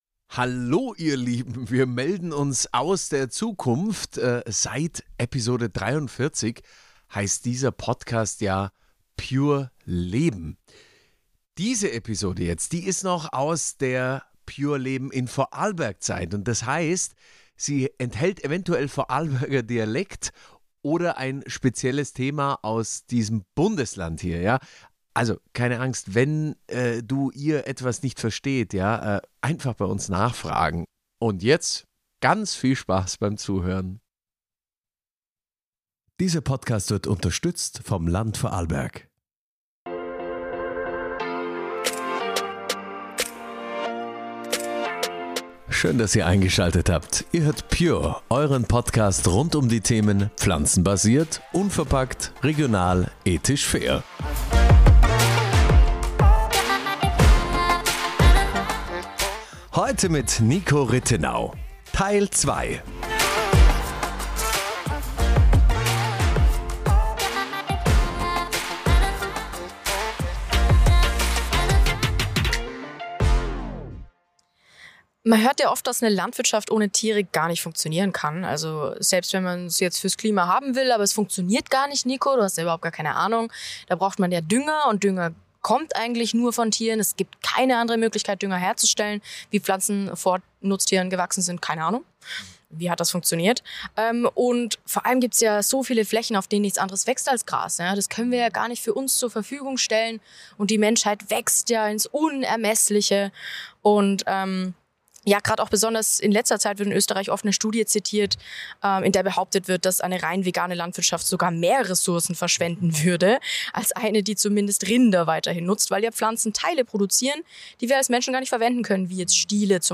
Im Teil 2 des Gesprächs mit Niko Rittenau, nehmen wir einerseits die Landwirtschaft unter die Lupe und ob es dazu Tierhaltung braucht.